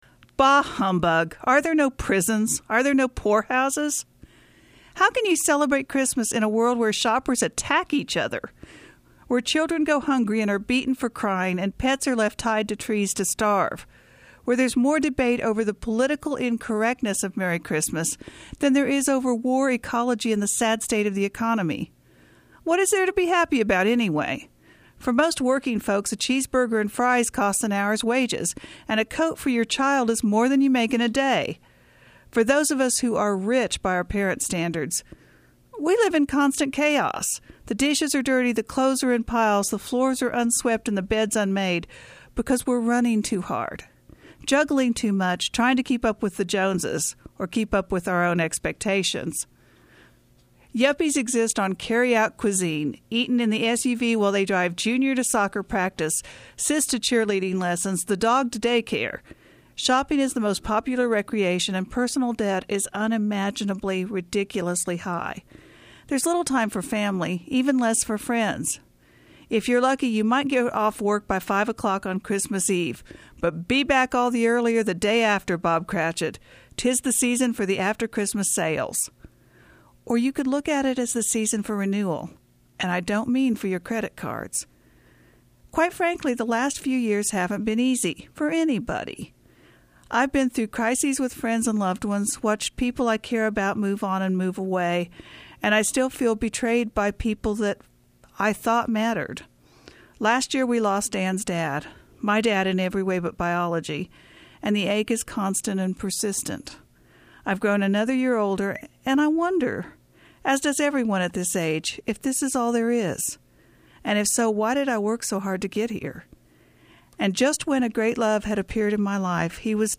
reads her Christmas column.